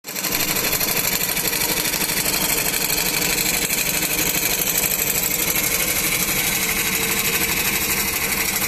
So here is the small video of cutting the pocket hole on the plate using scroll saw.